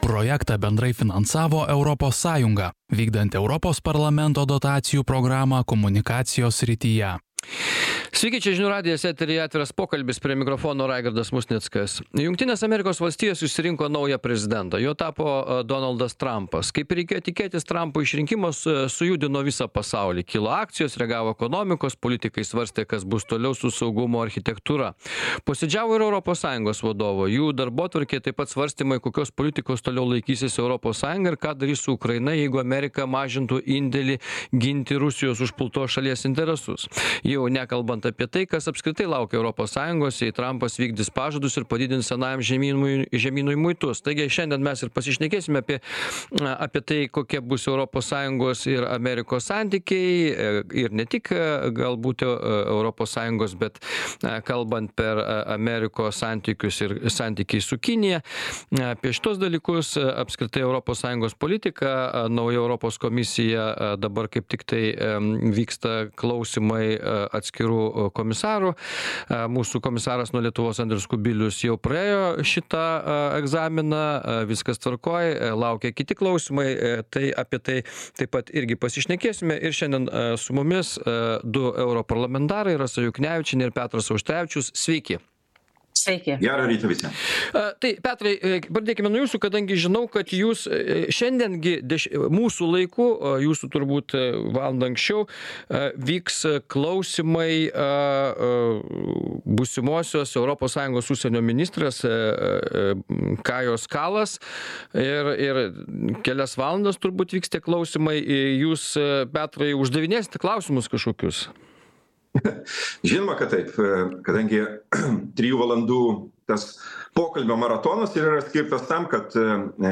Apie tai Žinių radijo laidoje „Atviras pokalbis“ diskutavo Europos Parlamento nariai Petras Auštrevičius ir Rasa Juknevičienė.